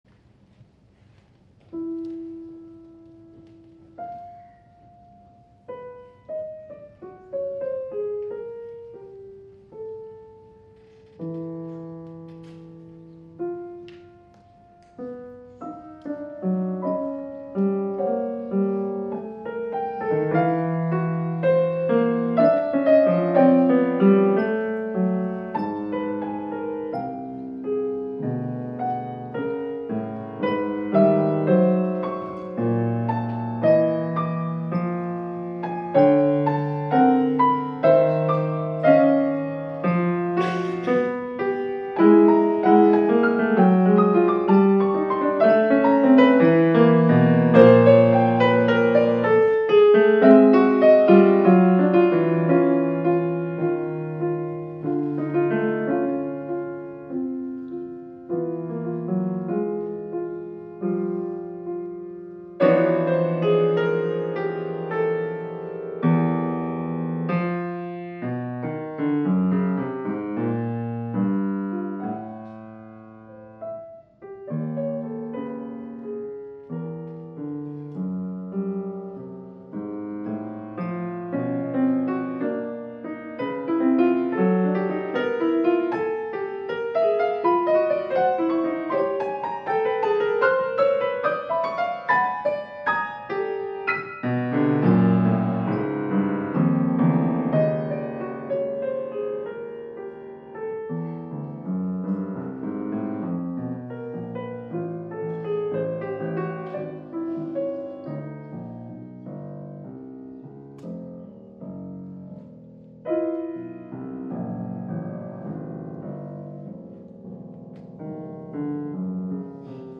Для фортепиано
Додекафония (12-tone system)
Запись произведена на концерте по композиции
По форме это двух-частная композиция 1-14 и 15-29 такты.
Термин Rubato дает исполнителю некоторую свободу при исполнении, хотя скорость четверной ноты может быть 60.